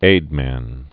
(ādmăn)